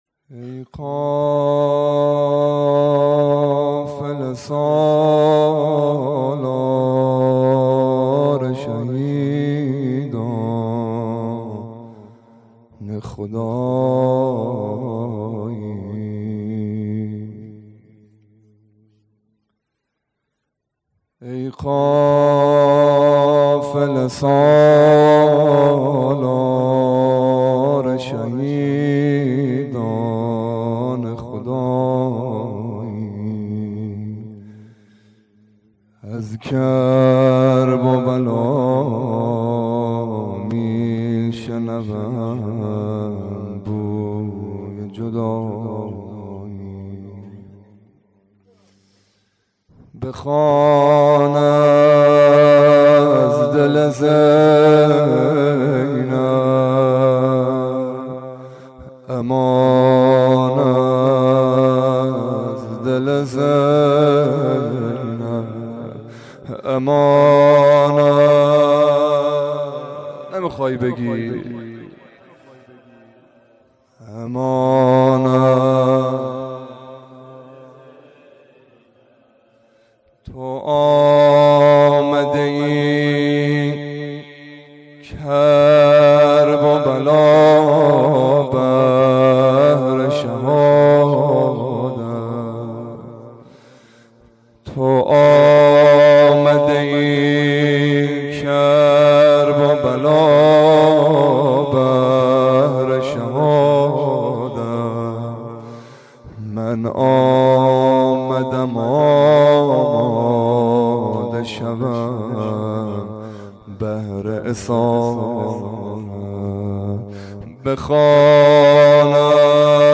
روضه
شب دوم محرم 93